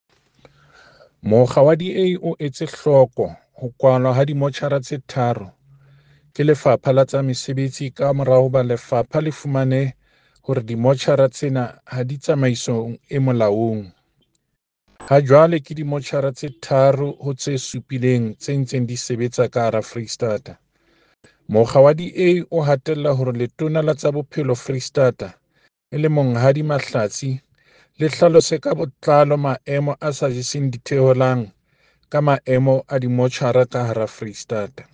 Sesotho soundbites by David Masoeu MPL